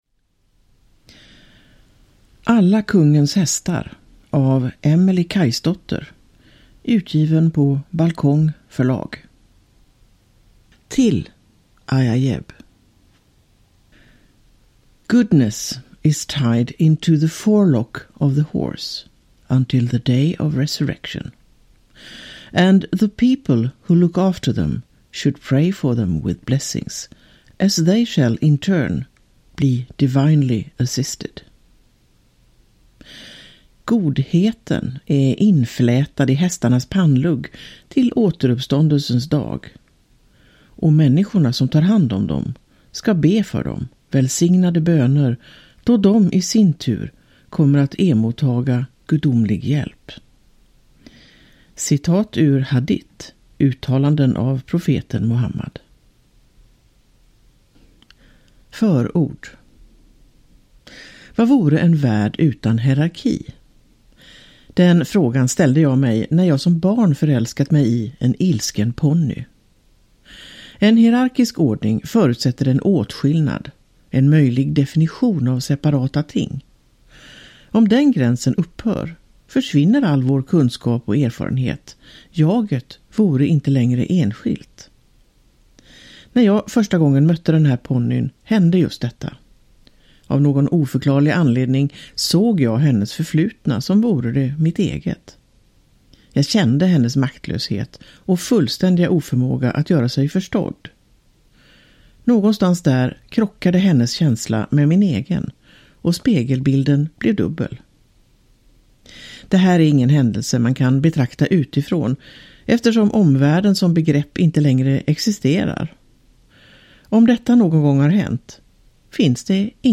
Alla Kungens Hästar – Ljudbok – Laddas ner